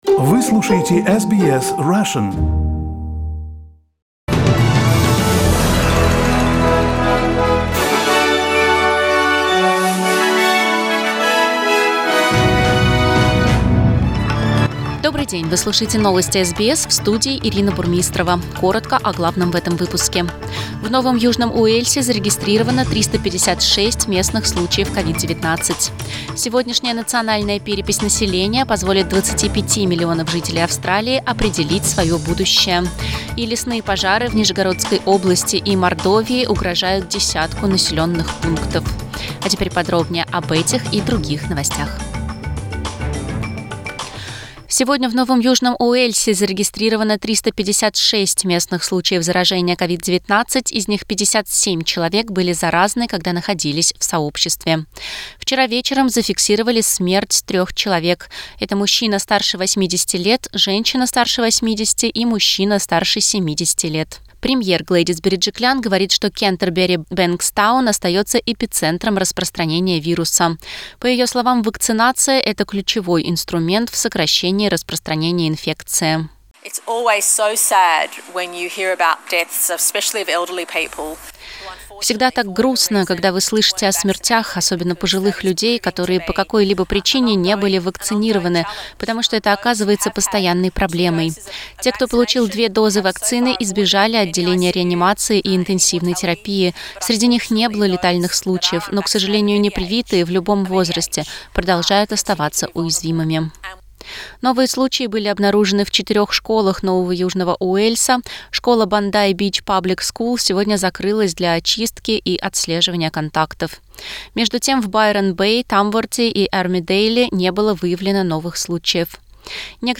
Новости SBS на русском языке - 10.08